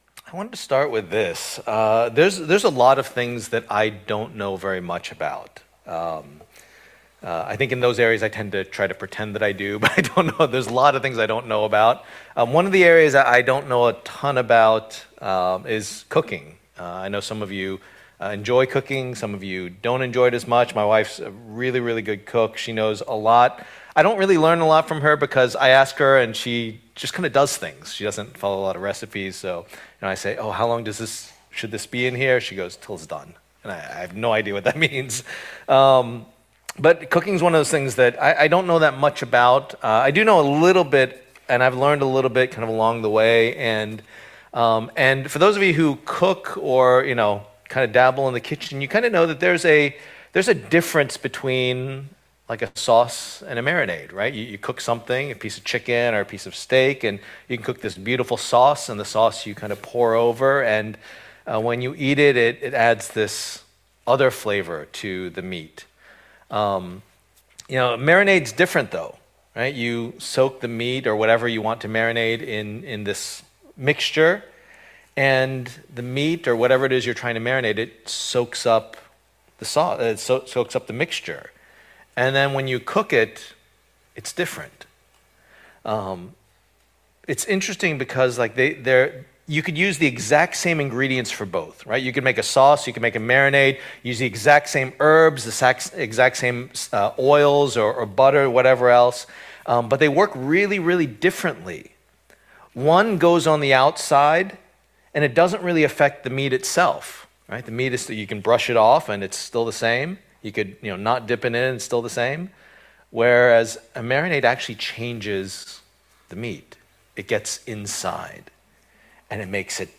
Passage: Colossians 4:2-18 Service Type: Lord's Day